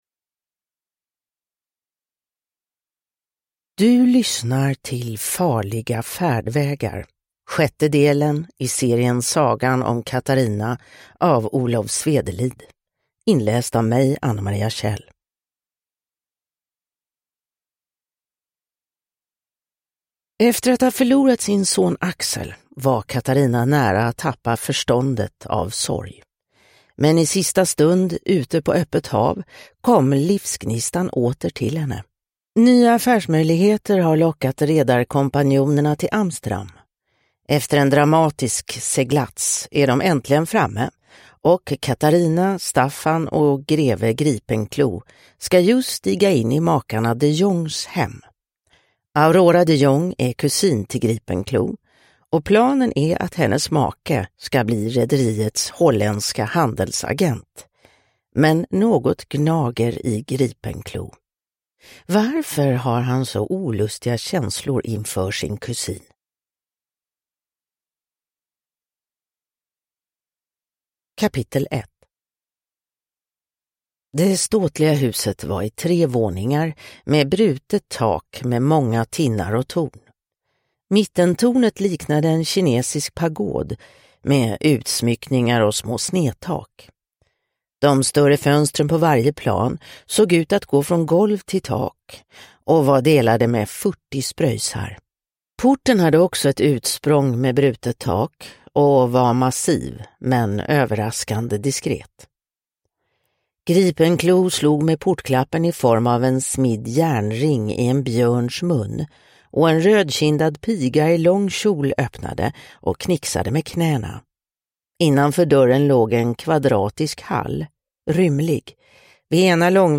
Farliga färdvägar – Ljudbok – Laddas ner